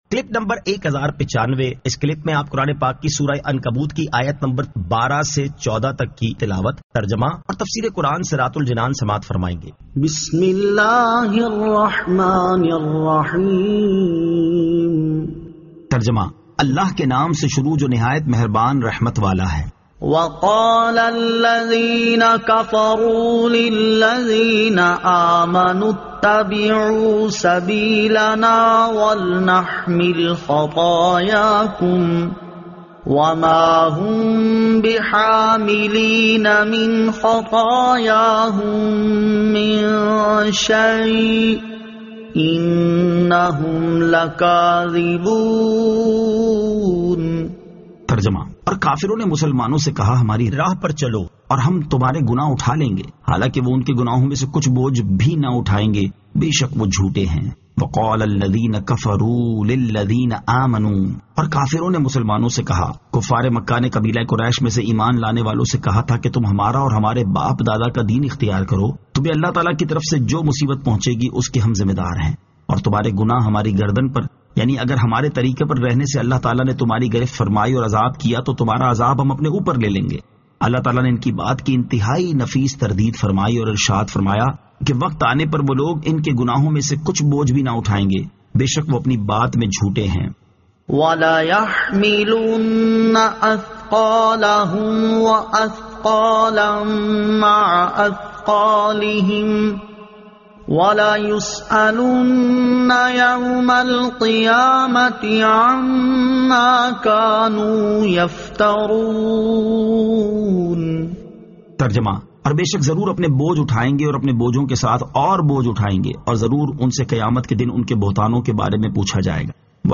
Surah Al-Ankabut 12 To 14 Tilawat , Tarjama , Tafseer